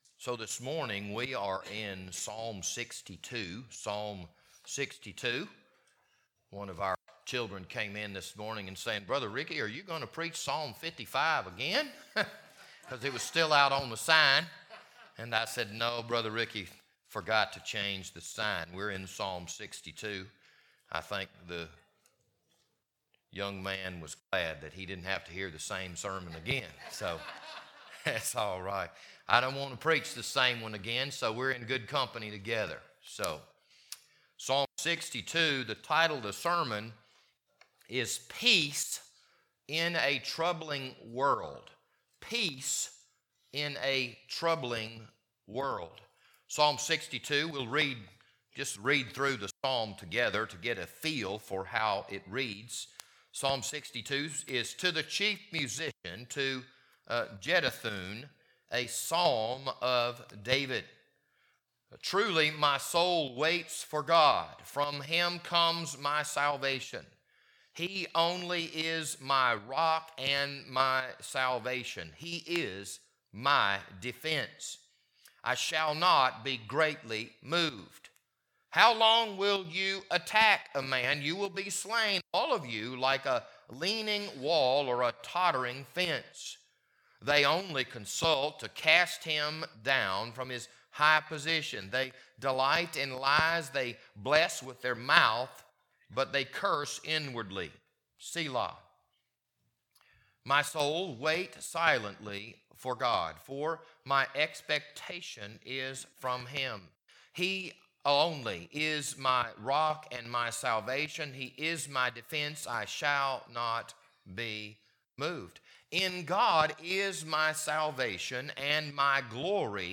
This Wednesday evening Bible study was recorded on October 5th, 2022.